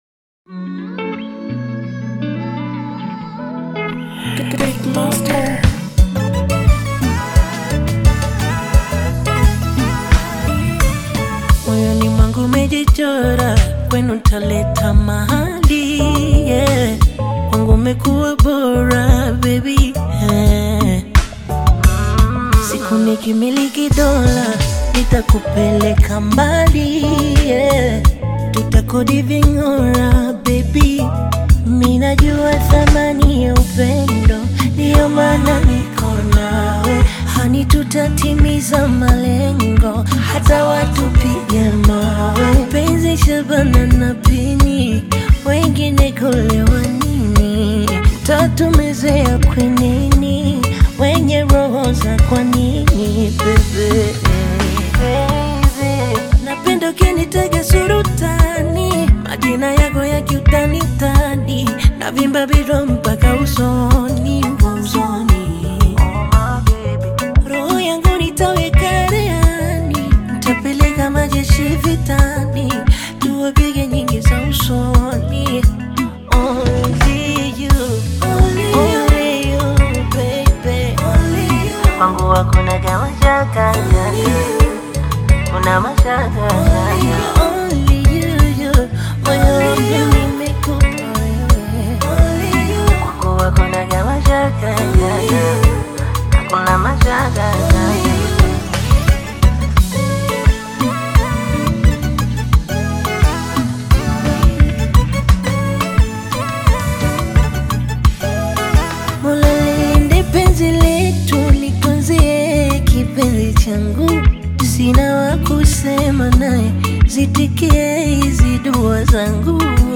Bongo Flava music track
Tanzanian Bongo Flava artist